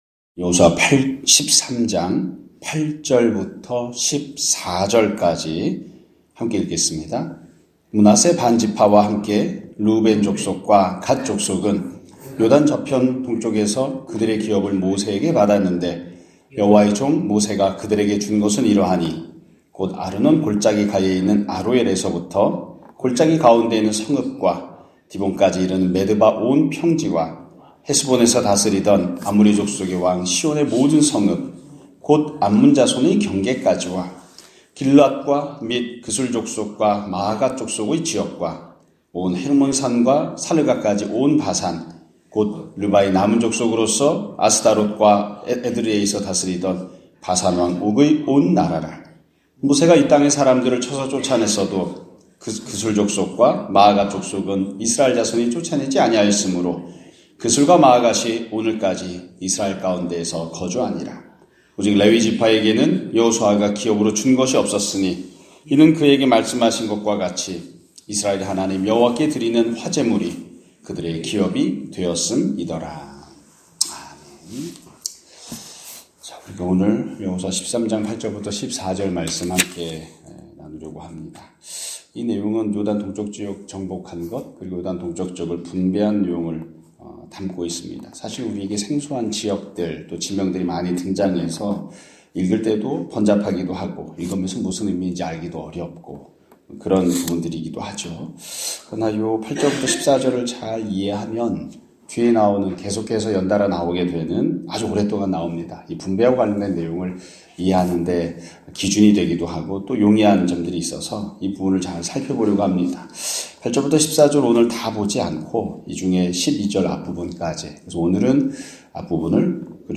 2024년 11월 18일(월요일) <아침예배> 설교입니다.